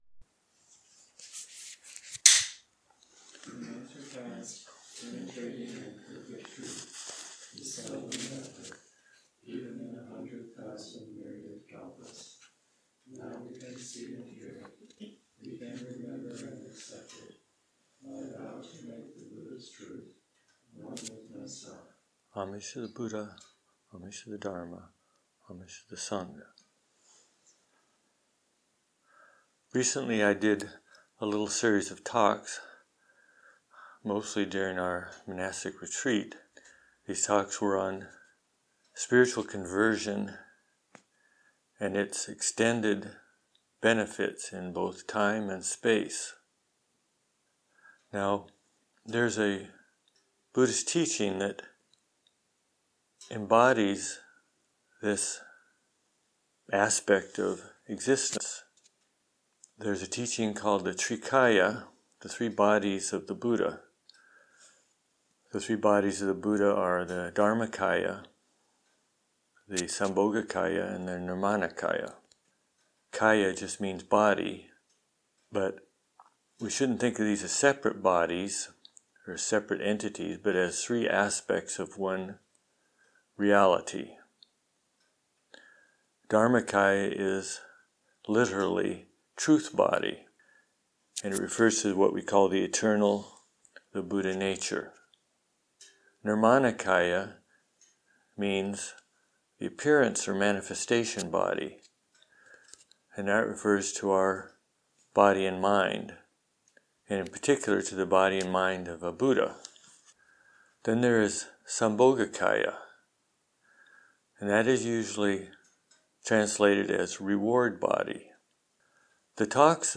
DHARMA TALKS—2023